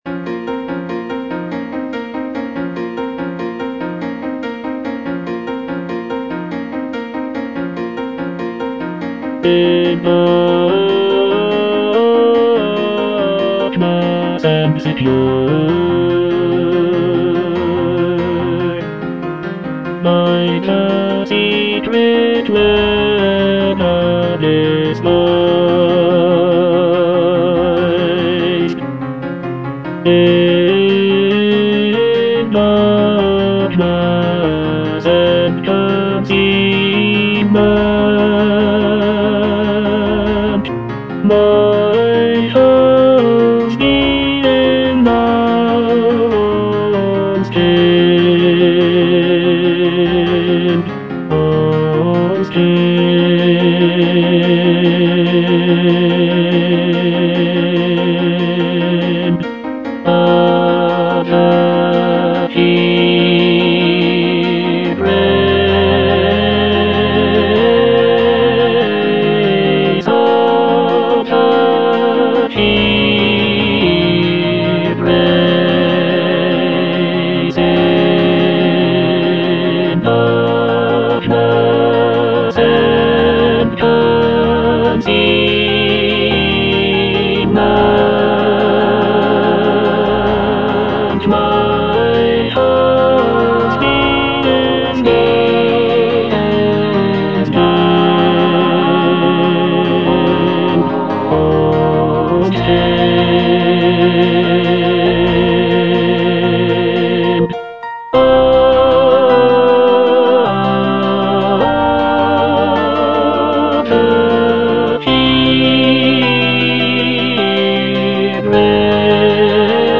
(tenor I) (Emphasised voice and other voices) Ads stop